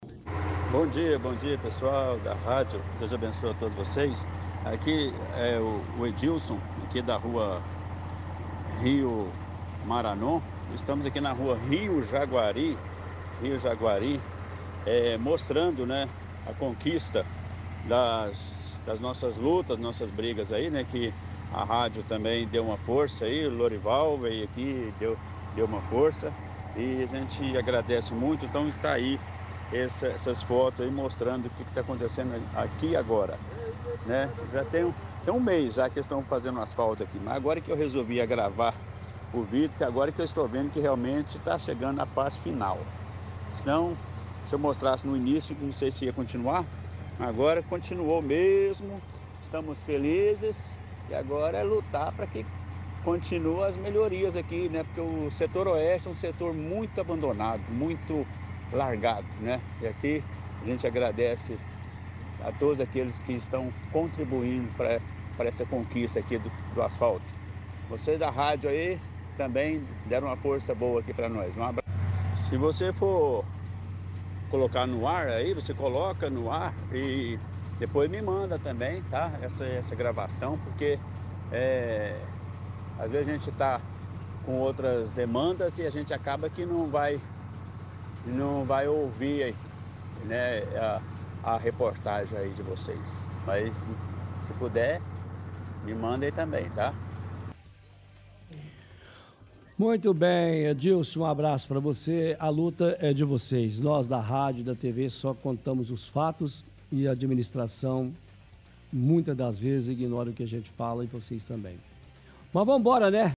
– Ouvinte diz que está mostrando fotos de uma rua no setor oeste da cidade que estava sendo asfaltada a mais de um mês, segundo ele, depois de começar a registrar, o serviço andou mais rápido.